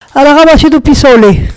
Sables-d'Olonne (Les)
locutions vernaculaires